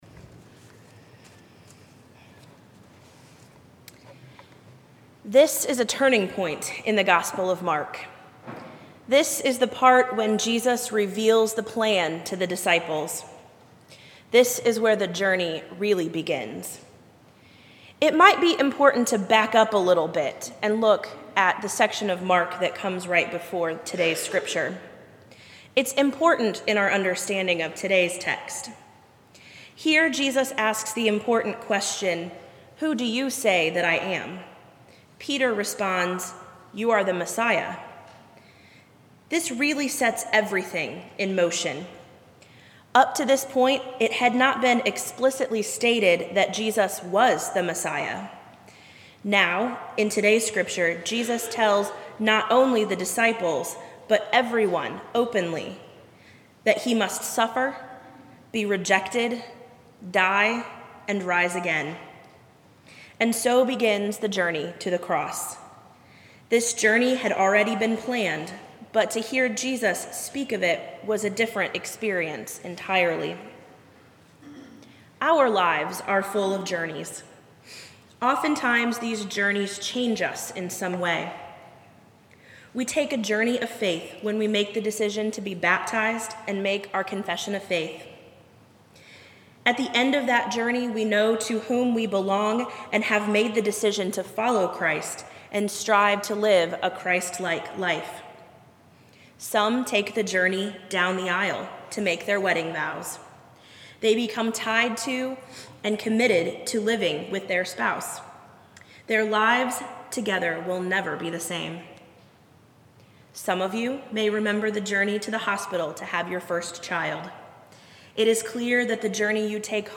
sermon preached at Vine Street on Sunday, March 4, 2012.